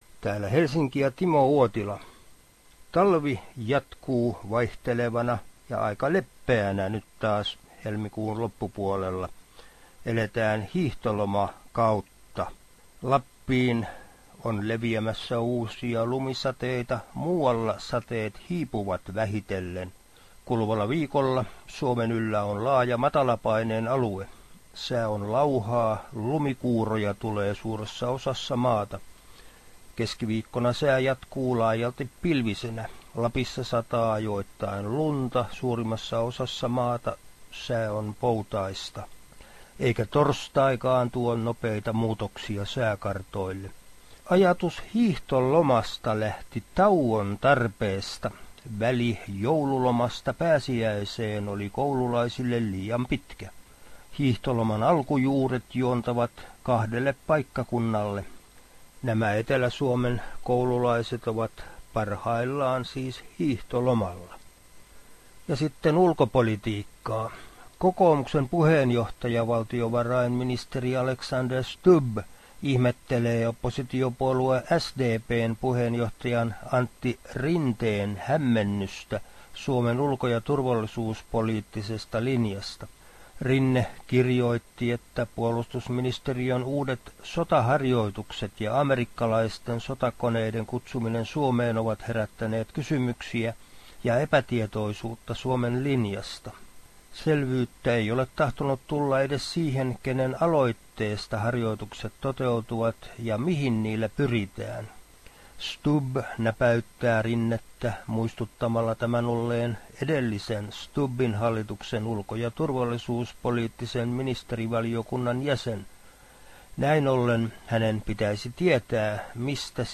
säännöllinen Suomen ajankohtaisraportti